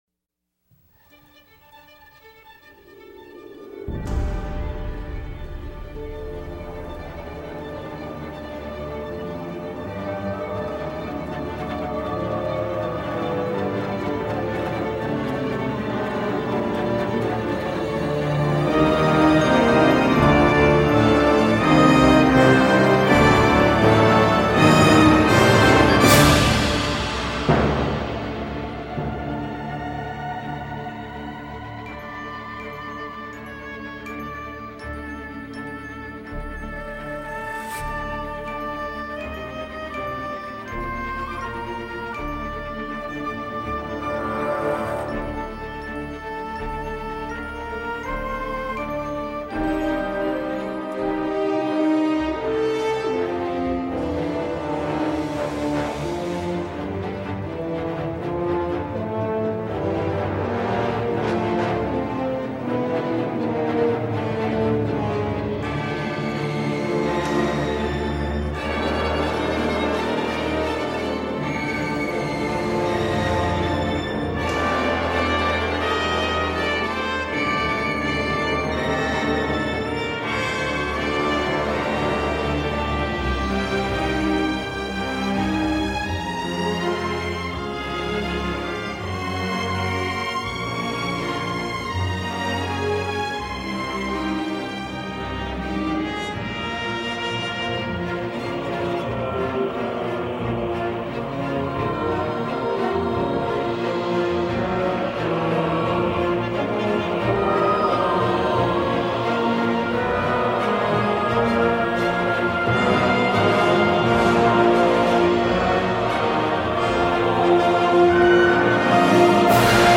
Gothique, aérien, sombre, éthéré, violent, sensible